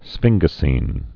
(sfĭnggə-sēn)